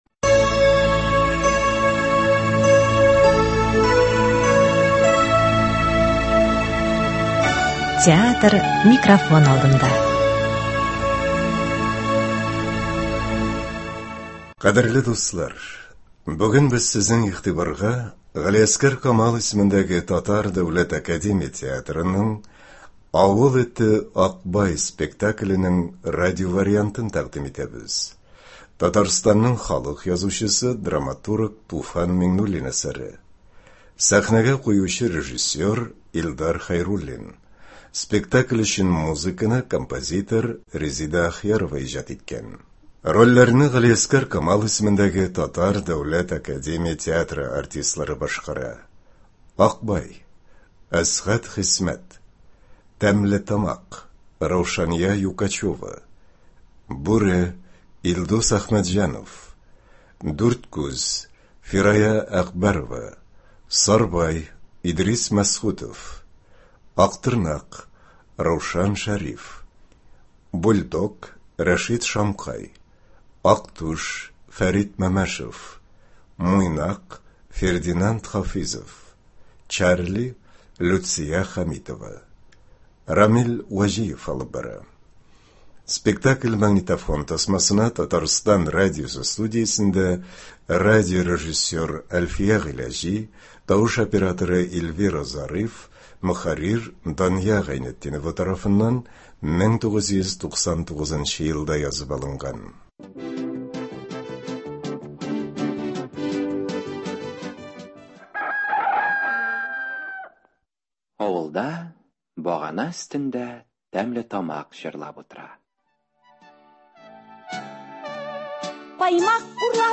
Радиоспектакль (25.12.23)